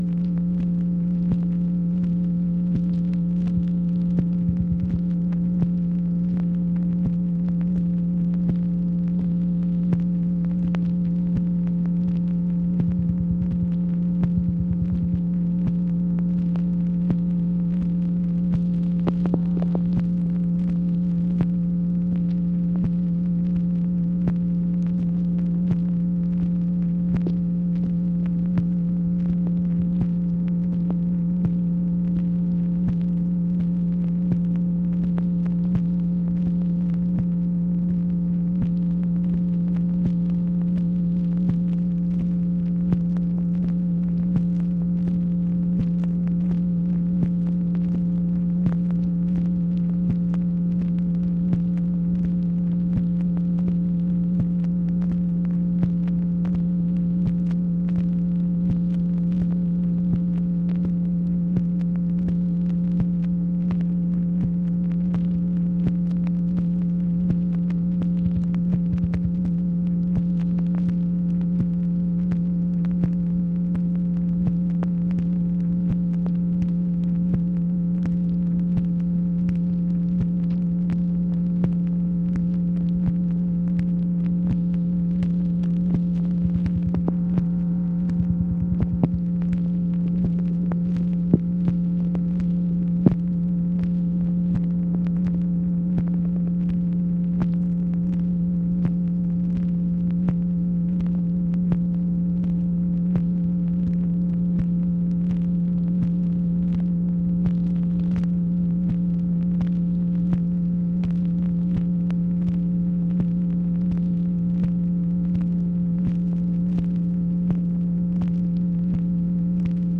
MACHINE NOISE, March 4, 1966
Secret White House Tapes | Lyndon B. Johnson Presidency